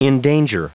Prononciation du mot endanger en anglais (fichier audio)
Prononciation du mot : endanger